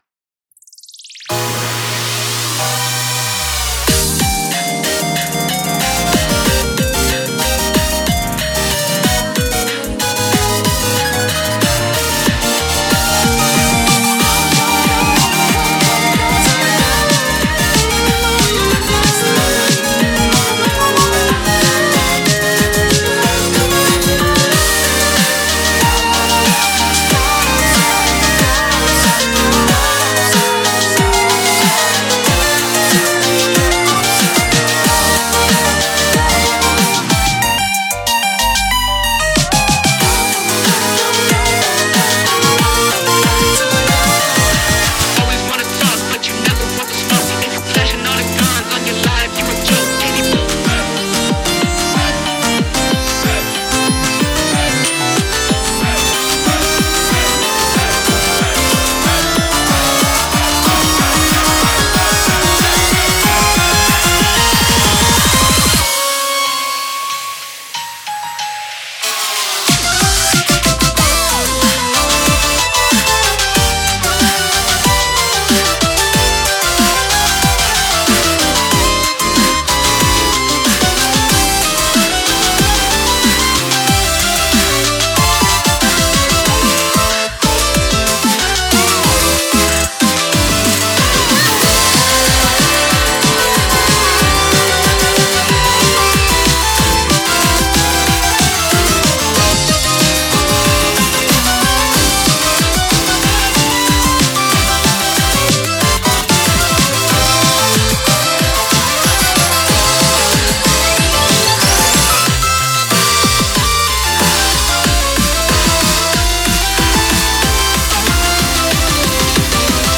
明るい雰囲気の曲です。
タグ あかるい